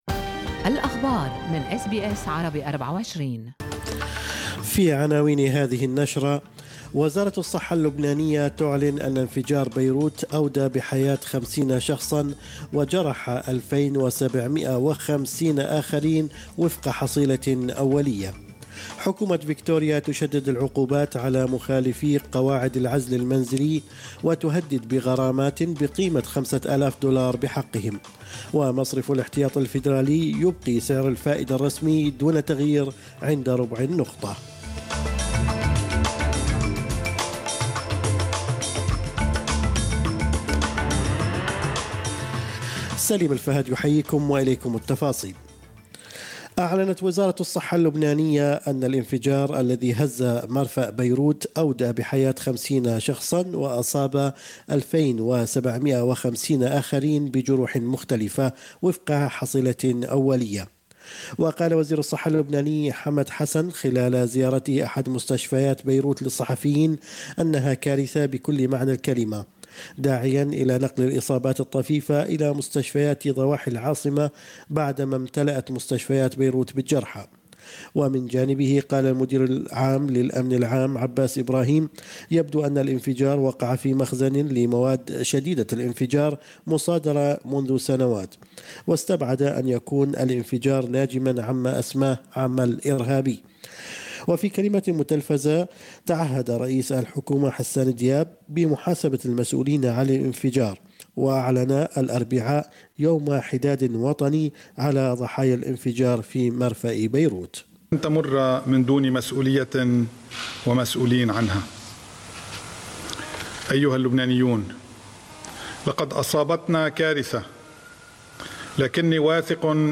نشرة أخبار الصباح 5/8/2020